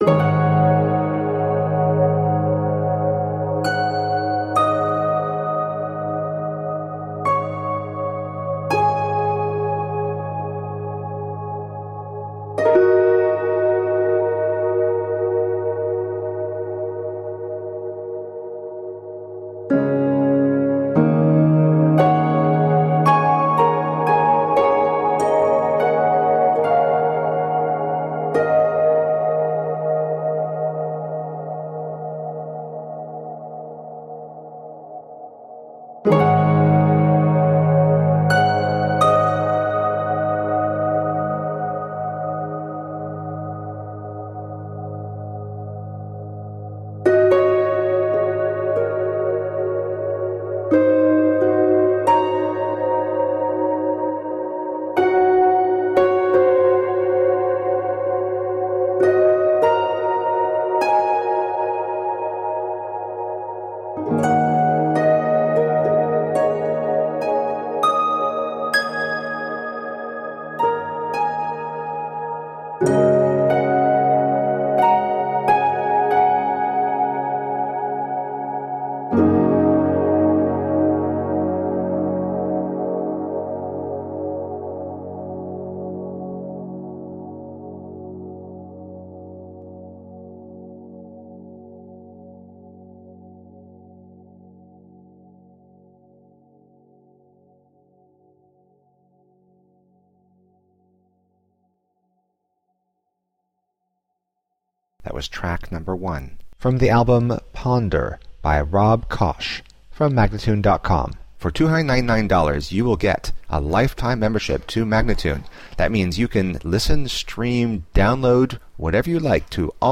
evocative and down-tempo describe the first half